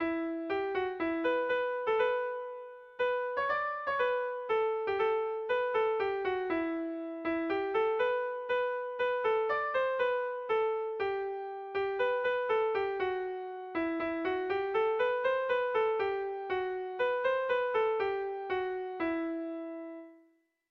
Sentimenduzkoa
Zuberoa < Basque Country
ABDEF